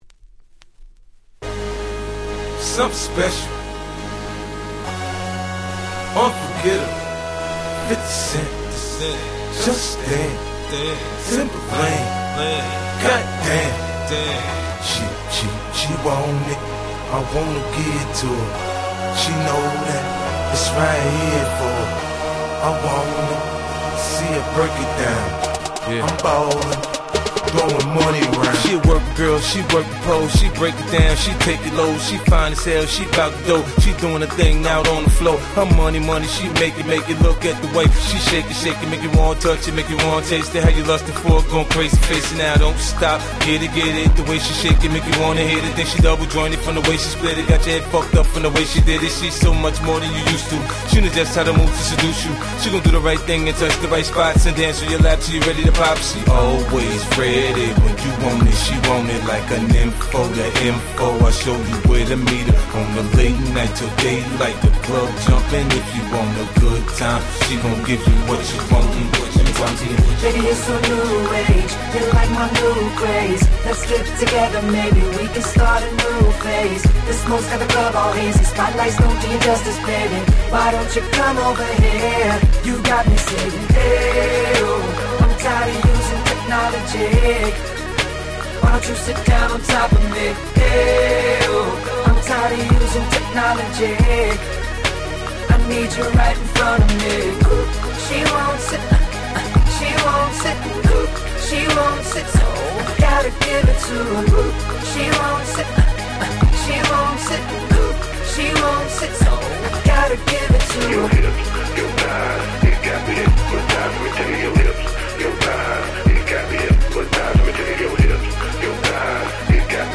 07' Super Hit Hip Hop !!